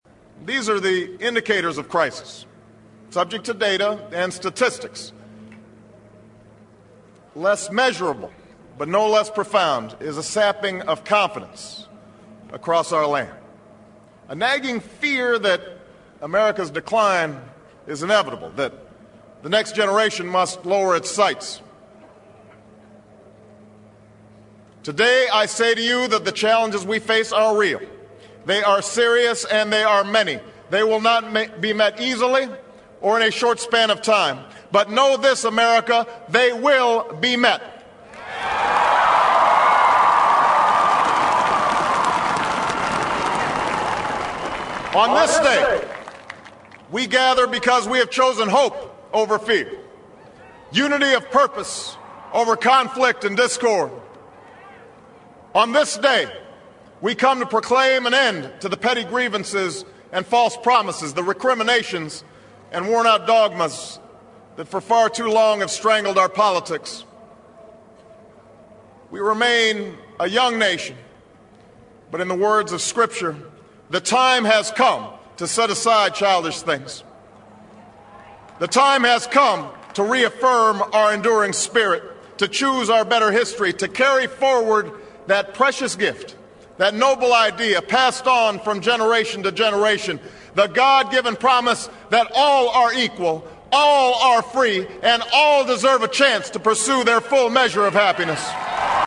偶像励志英语演讲 第106期:美国总统奥巴马就职演说(2) 听力文件下载—在线英语听力室